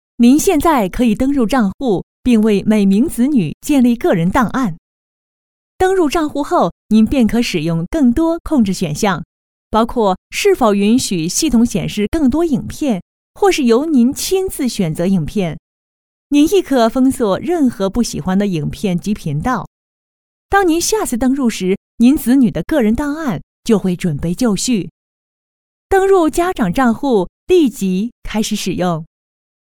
女225-课件PPT【电视操作 账户】
女225-课件PPT【电视操作   账户】.mp3